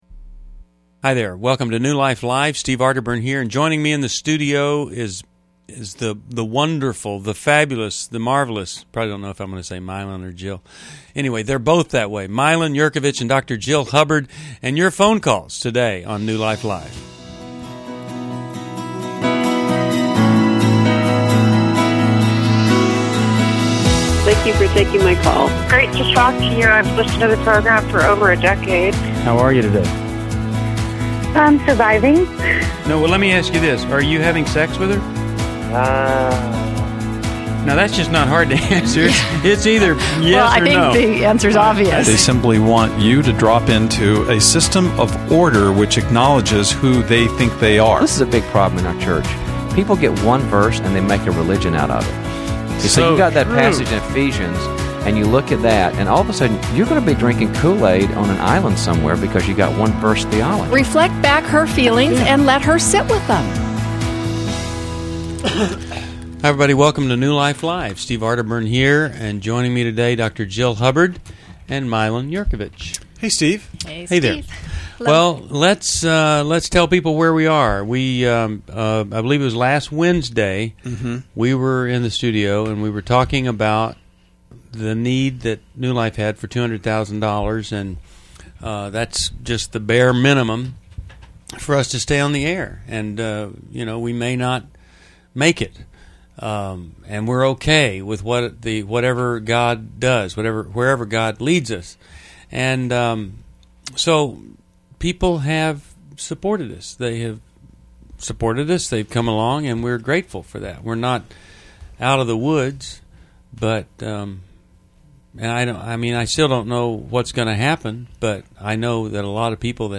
Explore forgiveness, reconciliation, and grief as callers seek guidance on self-forgiveness and recovery.
Caller Questions: 1.